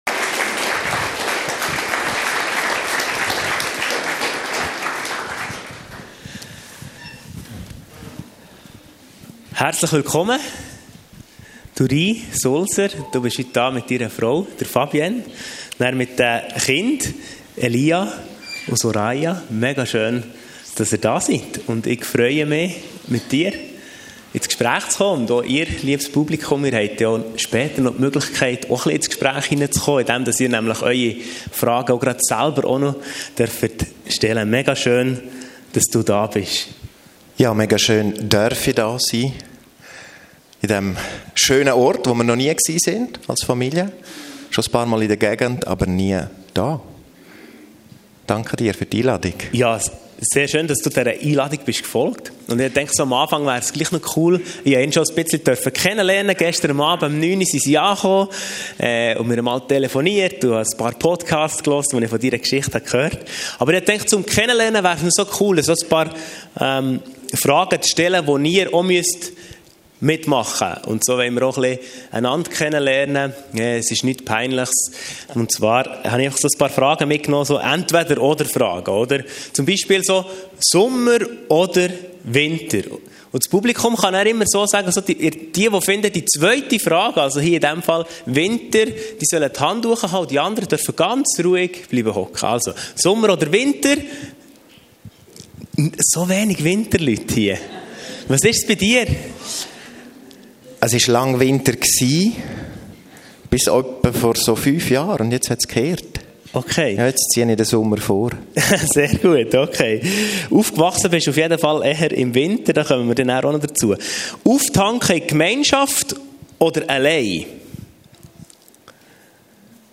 Talk Gottesdienst